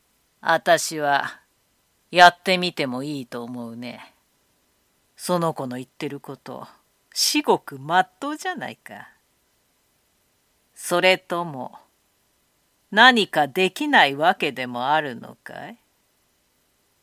ボイスサンプル
老女